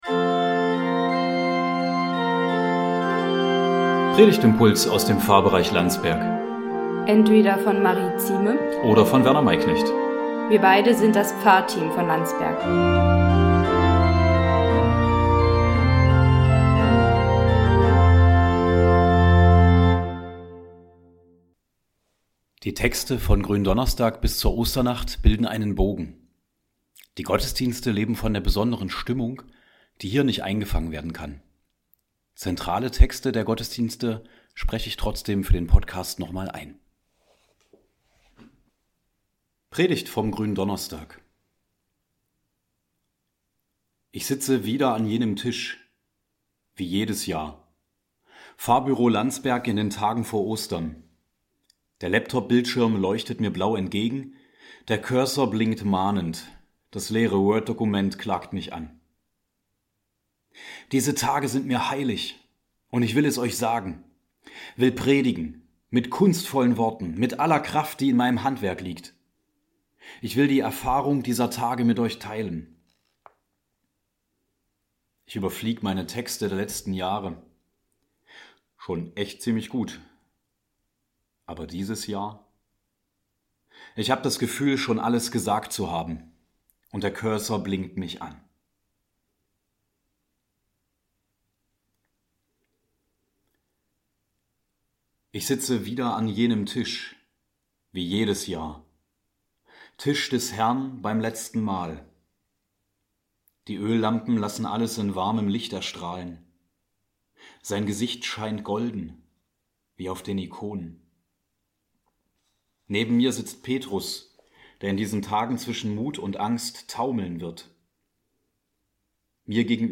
Predigtimpulse aus dem Pfarrbereich Landsberg